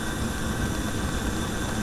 Gas Burn Loop 04.wav